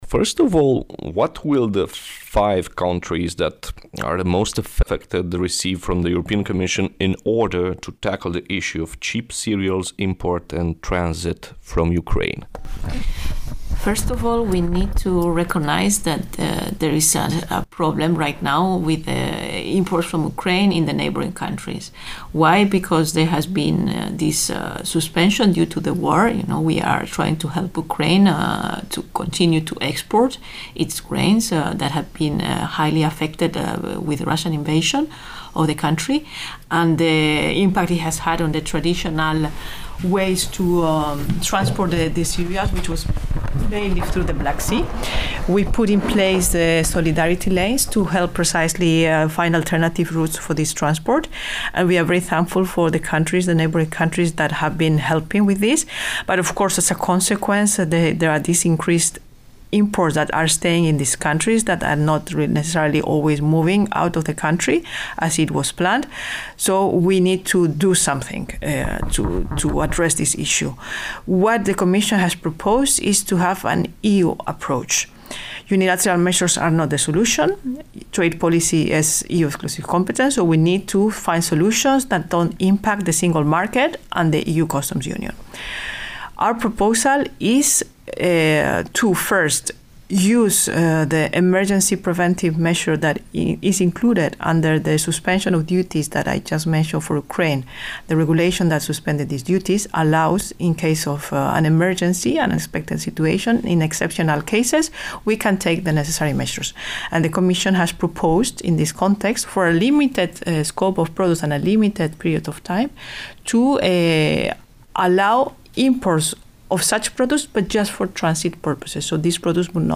ENGLISH VERSION OF THE INTERVIEW: